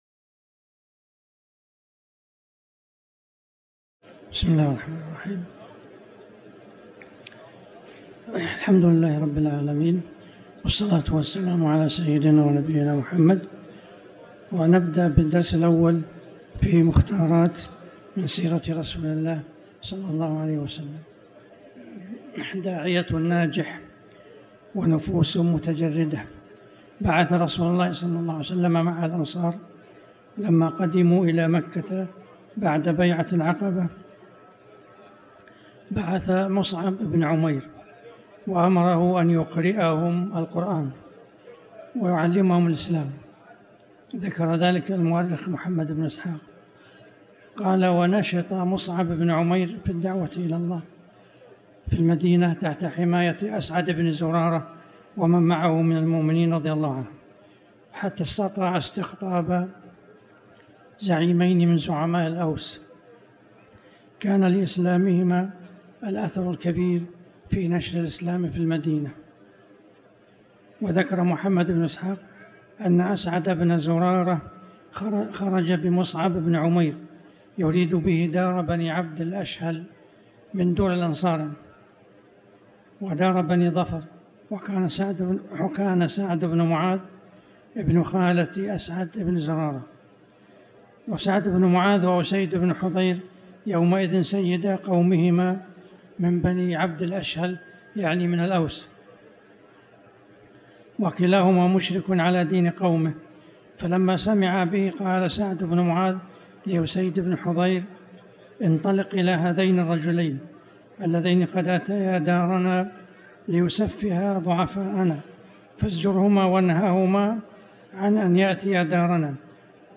تاريخ النشر ٤ ربيع الثاني ١٤٤٠ هـ المكان: المسجد الحرام الشيخ